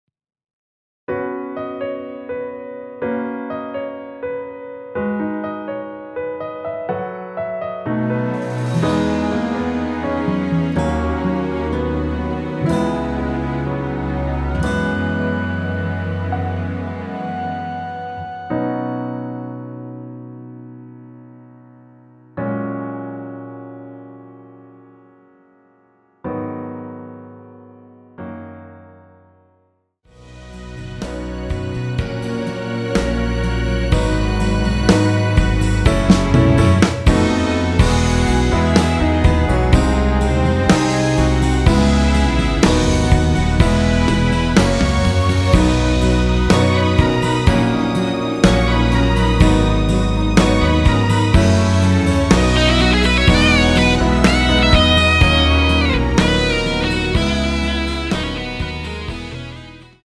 원키에서(-2)내린 MR입니다.
◈ 곡명 옆 (-1)은 반음 내림, (+1)은 반음 올림 입니다.
앞부분30초, 뒷부분30초씩 편집해서 올려 드리고 있습니다.